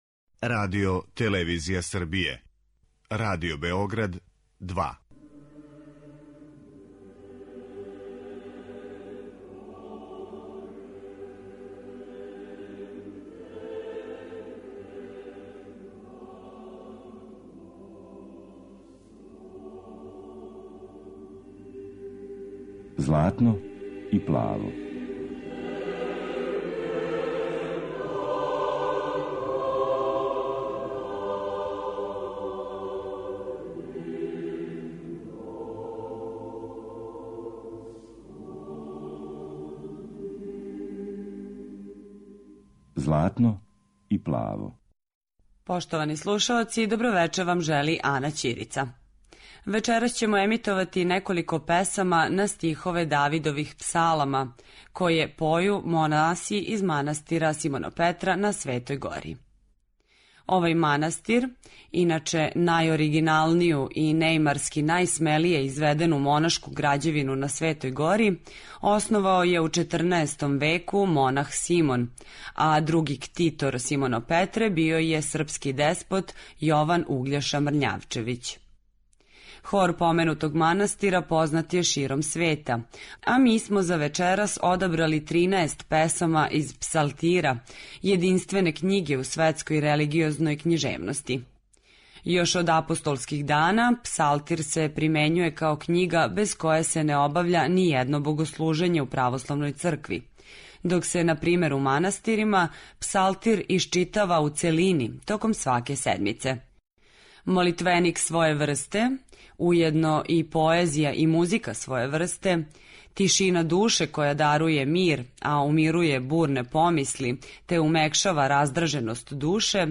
Хор манастира Симонопетра
Емитоваћемо неколико песама на стихове Давидових псалама, које поју монаси из манастира Симонопетра на Светој Гори.
Емисија посвећена православној духовној музици.